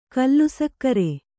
♪ kallusakkare